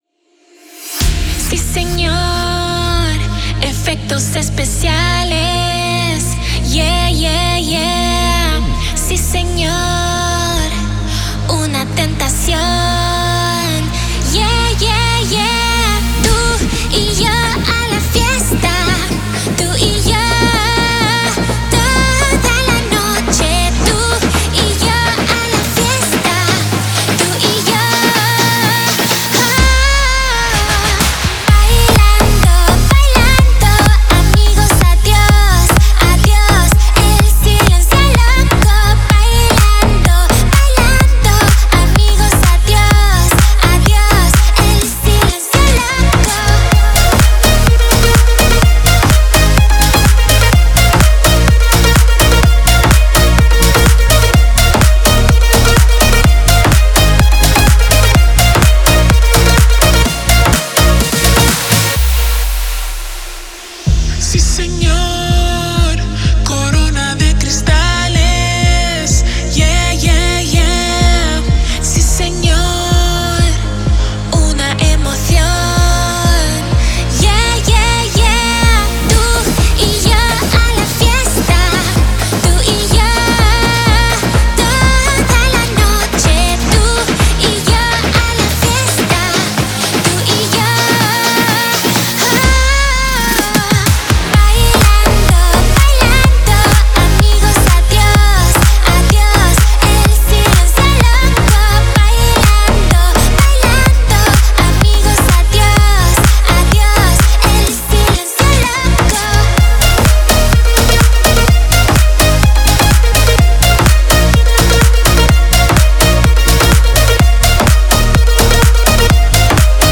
это зажигательная латинская поп-песня